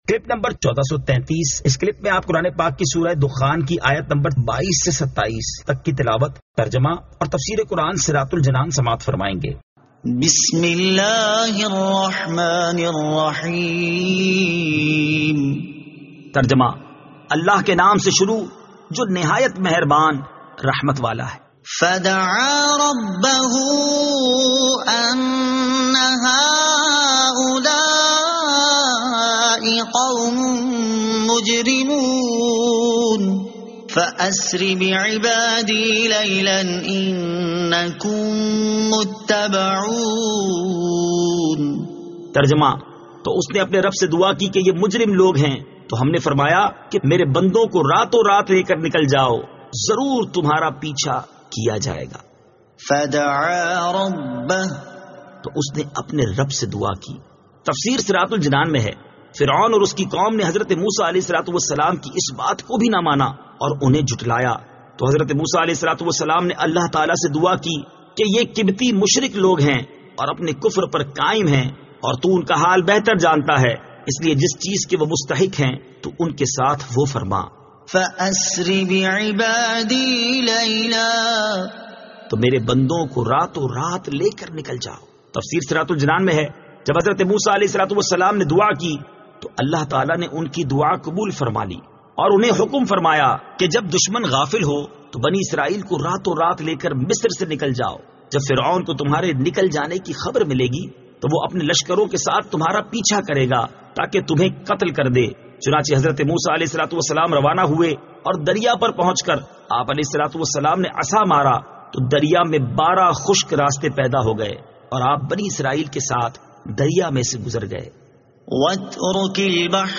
Surah Ad-Dukhan 22 To 27 Tilawat , Tarjama , Tafseer